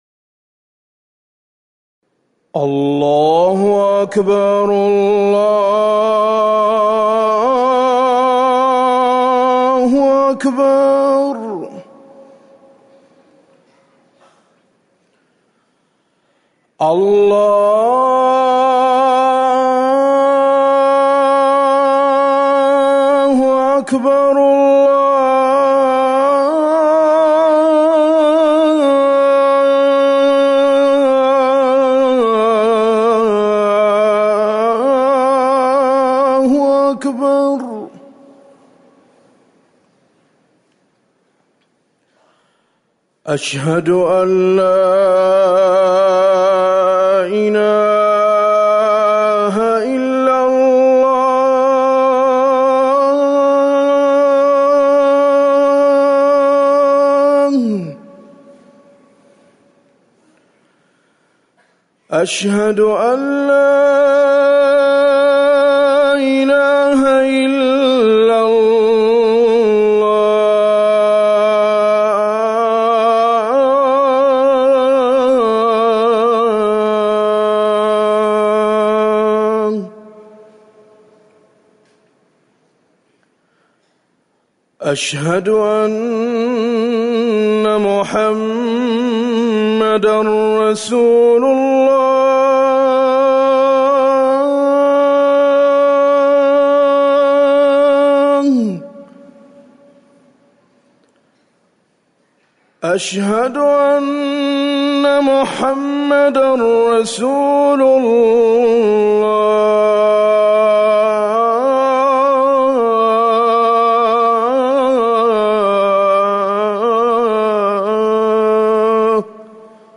أذان الفجر الأول - الموقع الرسمي لرئاسة الشؤون الدينية بالمسجد النبوي والمسجد الحرام
تاريخ النشر ١٧ محرم ١٤٤١ هـ المكان: المسجد النبوي الشيخ